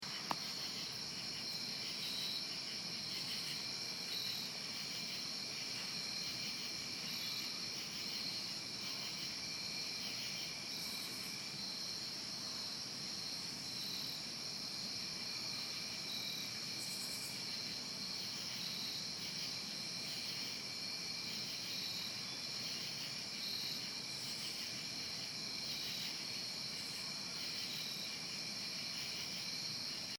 Terug bij het appartement werd ik begroet door een nachtelijk concert (klik